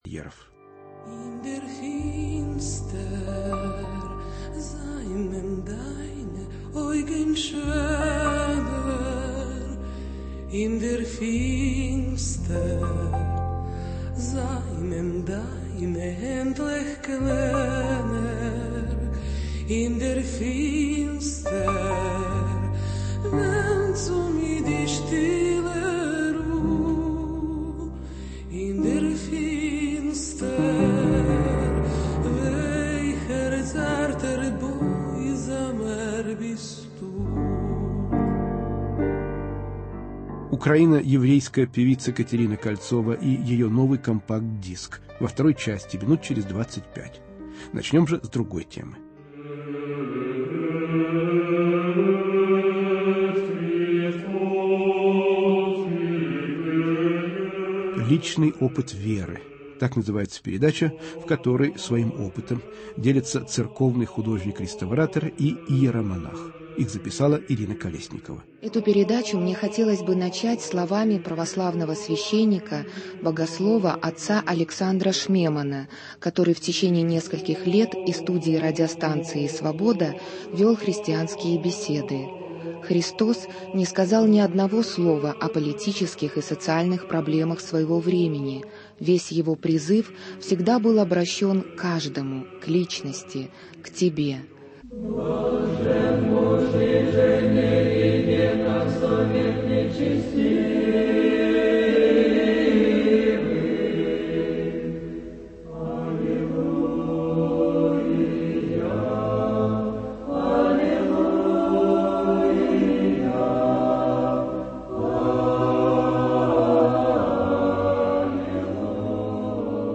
Личный опыт веры. Рассказывают церковный художник-реставратор и иеромонах.